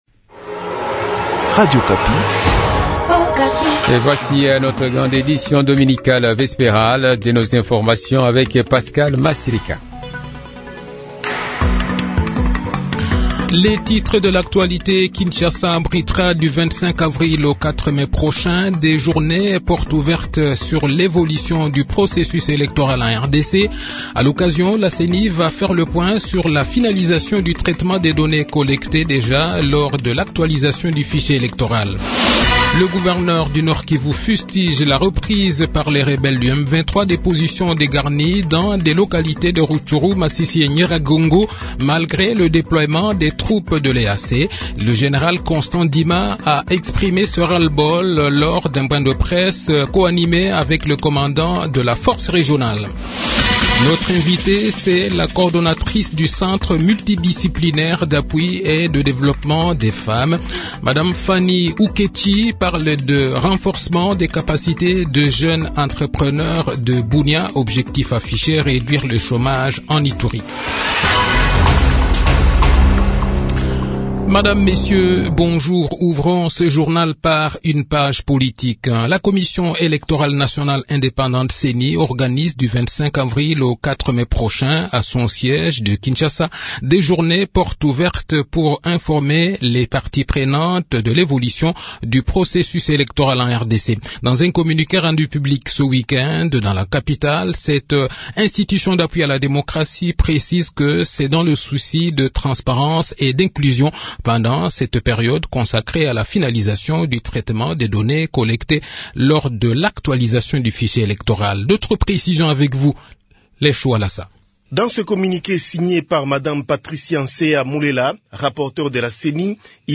Le journal d 18 h, 23 Avril 2023